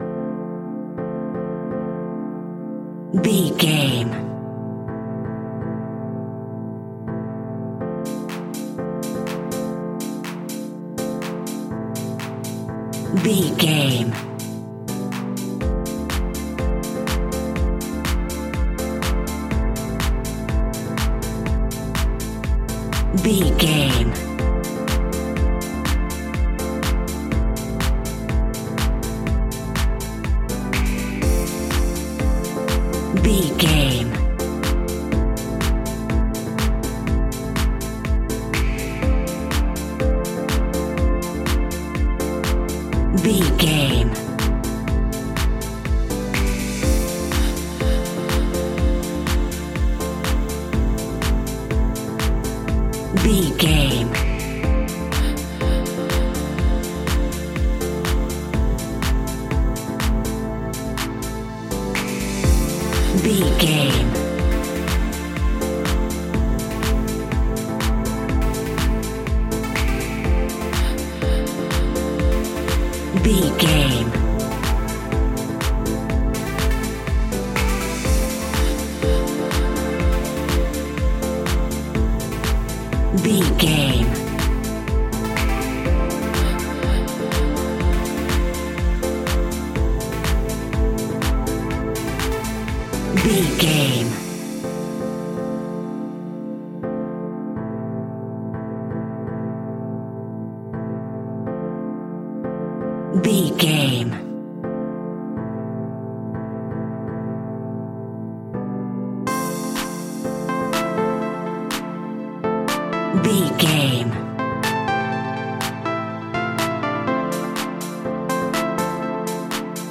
Aeolian/Minor
groovy
smooth
uplifting
drum machine
electro house
funky house
synth drums
synth leads
synth bass